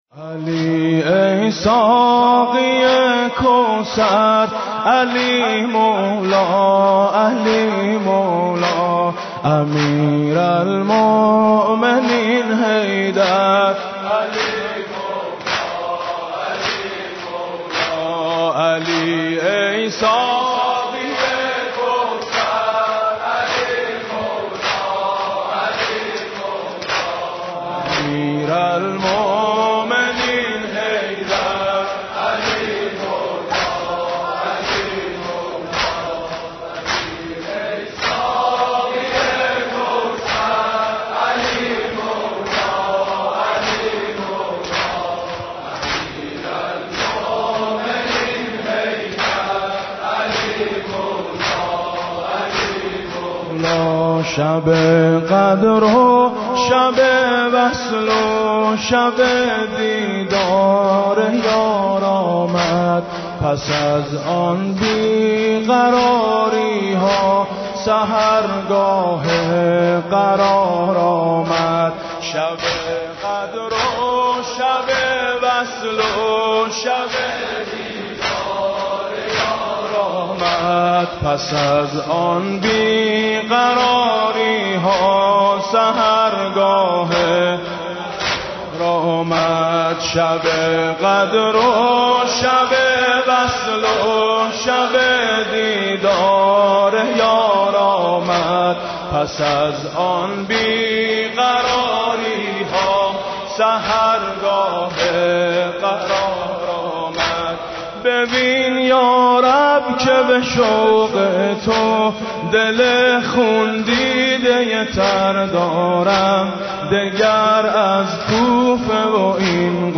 دانلود نوحه جدید مداحی شب قدر